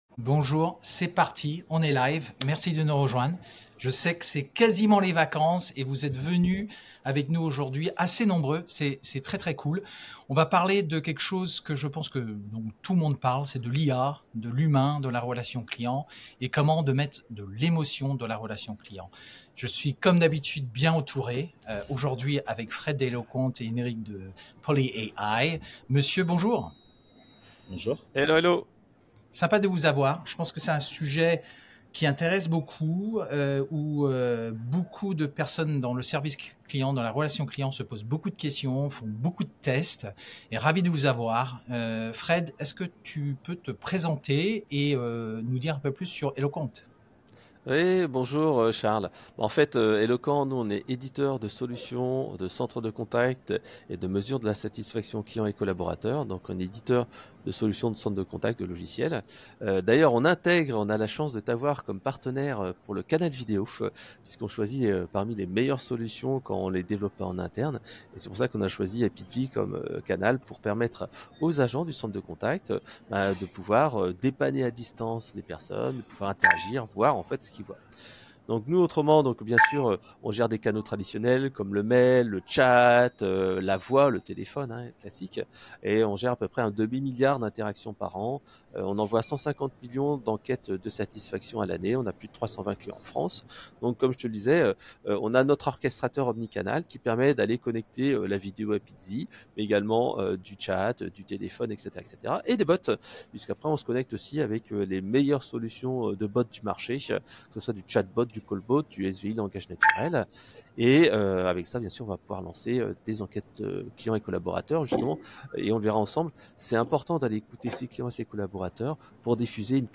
Lors d'un webinaire organisé par Apizee, nous avons analysé les opportunités offertes par l’IA + le relationnel humain pour une stratégie de relation client gagnante.